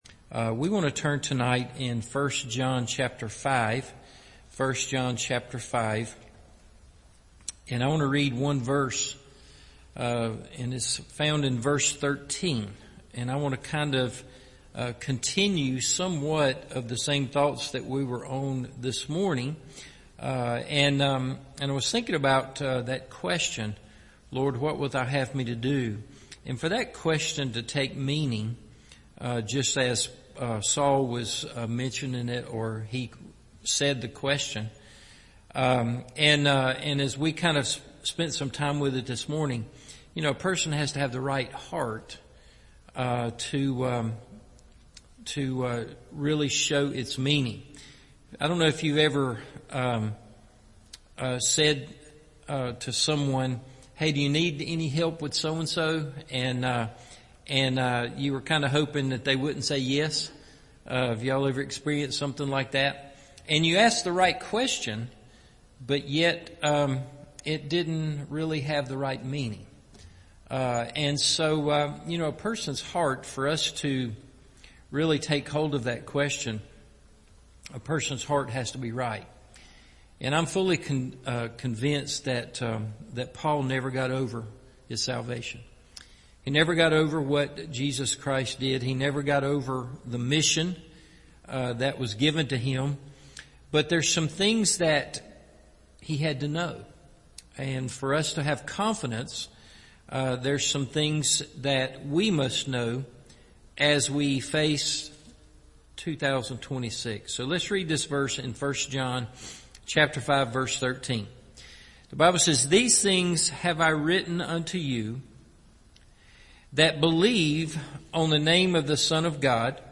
What God Wants Us To Know – Evening Service